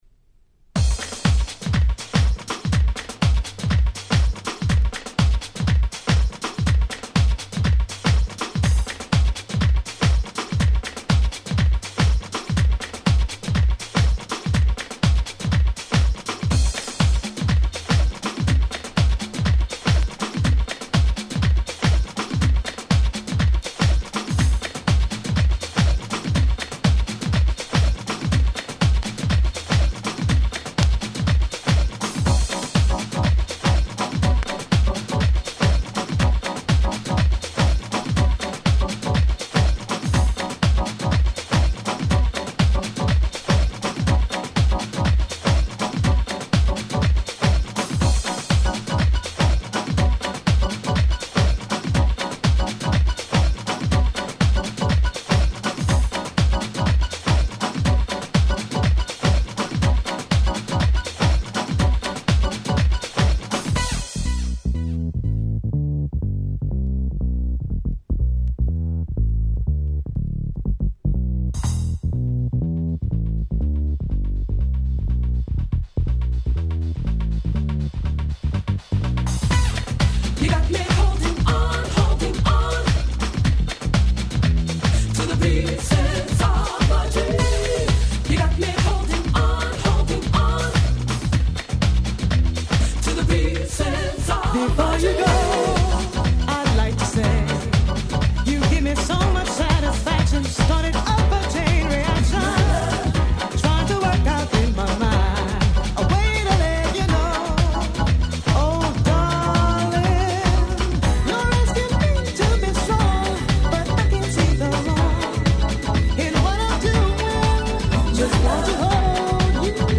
94' Nice Vocal House/Acid Jazz !!